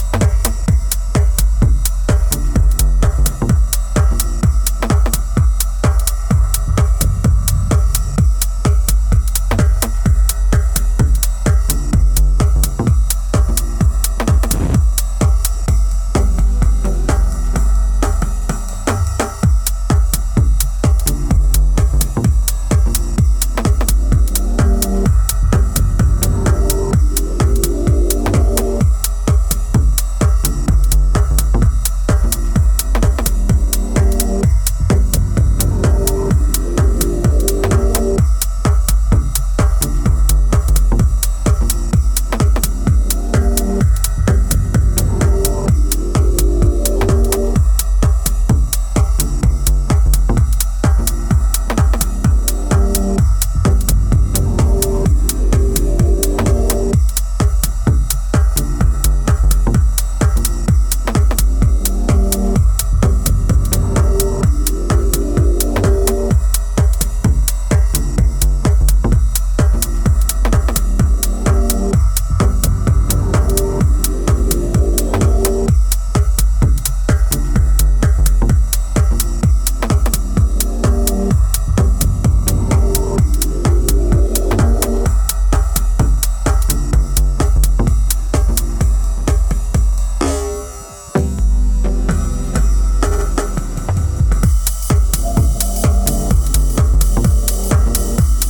2x12inch Vinyl